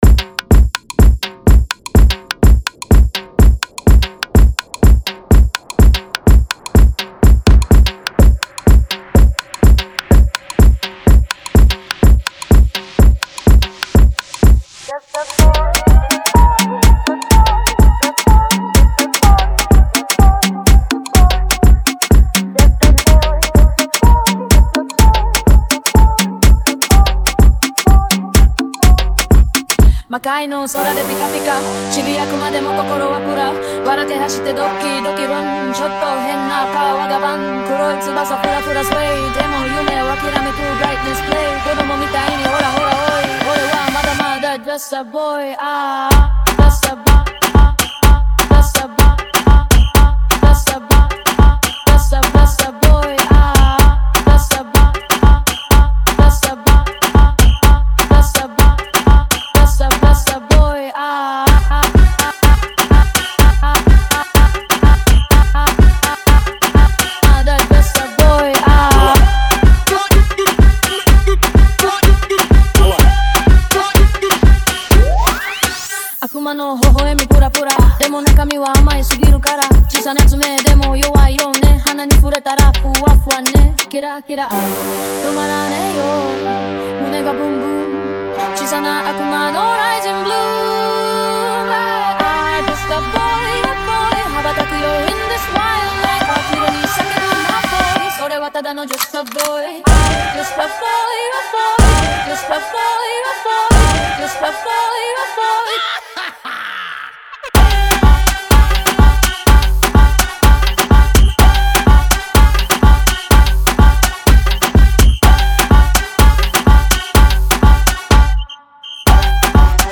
• Clean and professional mixing
• Balanced sound output
• Mix Type: DJ Mashup / Party Mix
• Genre: DJ Mashup / Club Mix
• Type: Extended / DJ Edit
• High Energy DJ Mix
• Smooth Transitions
• Modern sound design